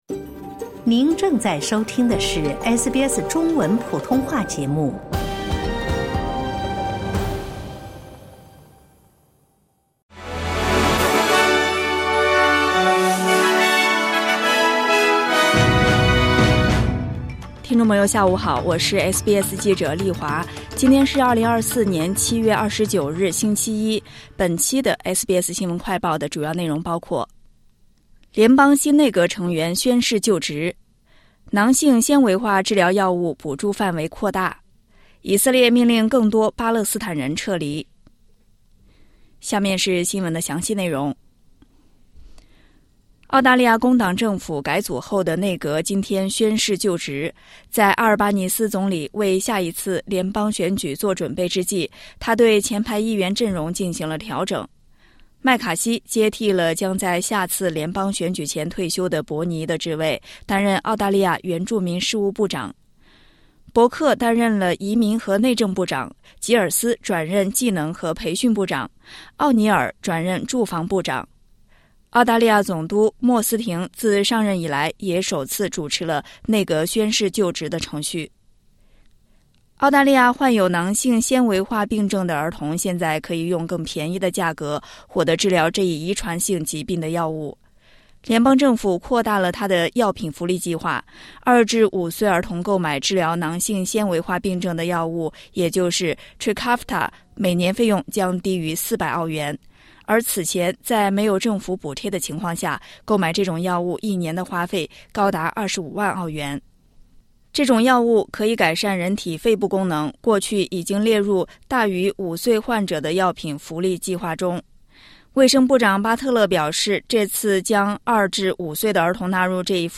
【SBS新闻快报】联邦新内阁成员宣誓就职